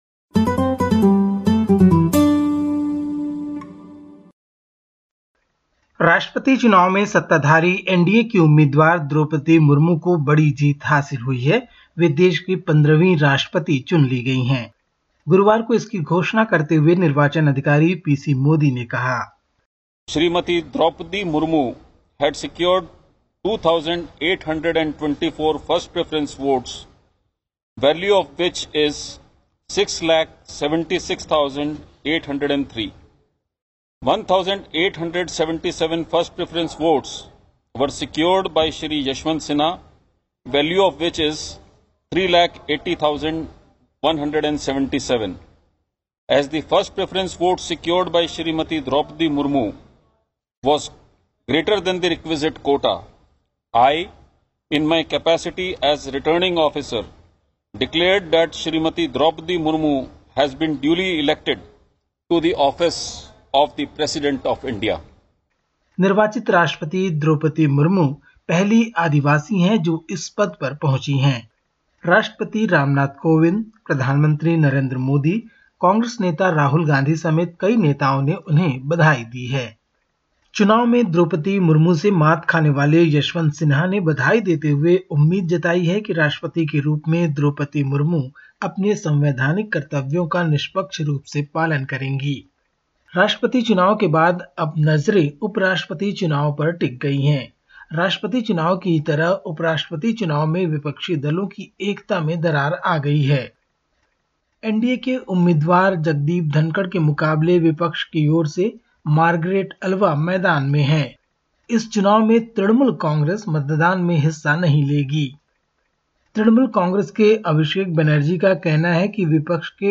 Listen to the latest SBS Hindi report from India. 22/07/2022